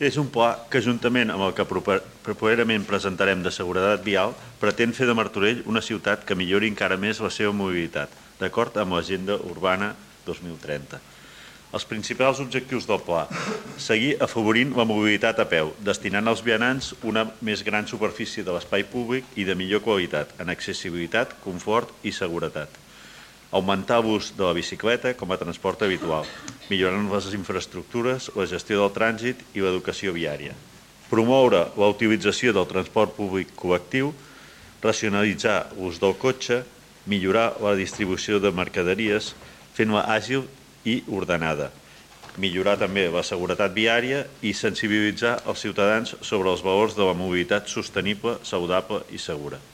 PLe Municipal. Juliol de 2025
Lluís Sagarra, regidor de Seguretat Ciutadana i Mobilitat